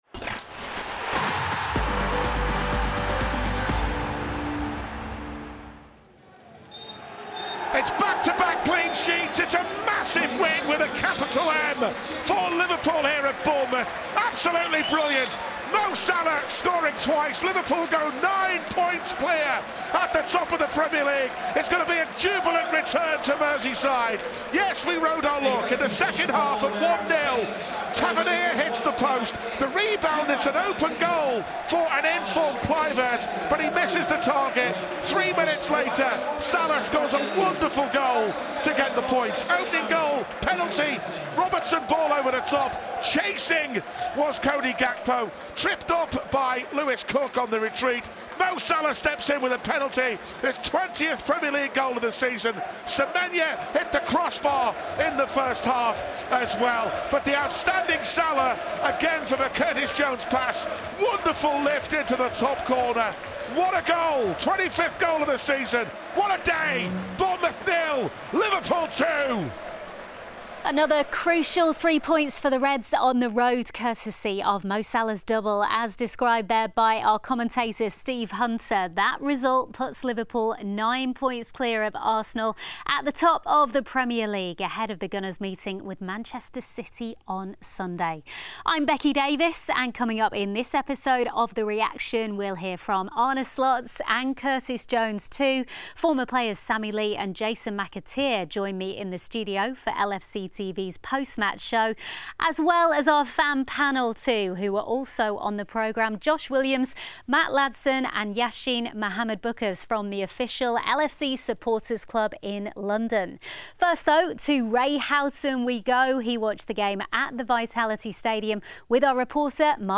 Arne Slot and Curtis Jones reflect on Liverpool’s 2-0 win away to Bournemouth after Mo Salah’s brace put the Reds nine points clear at the top of the Premier League ahead of Arsenal’s meeting with Manchester City on Sunday.